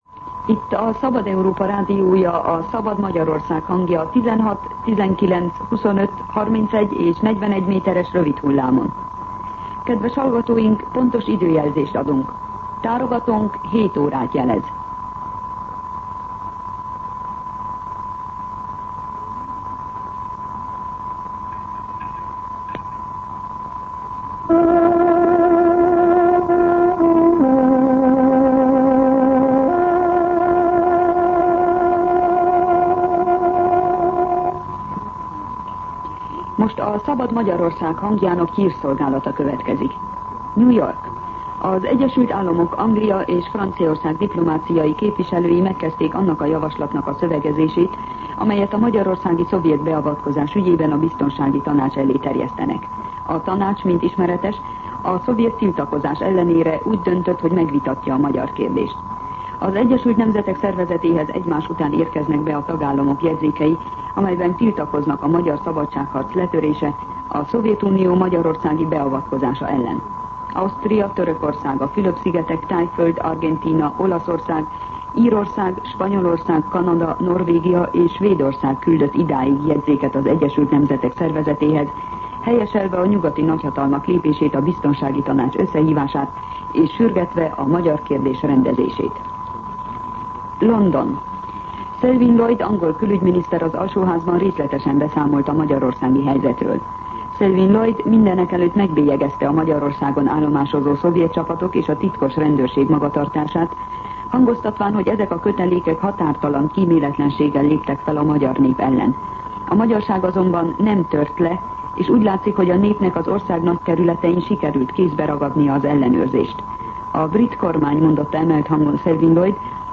07:00 óra. Hírszolgálat